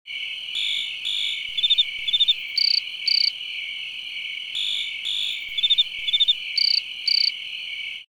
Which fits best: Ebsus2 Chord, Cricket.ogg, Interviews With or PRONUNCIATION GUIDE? Cricket.ogg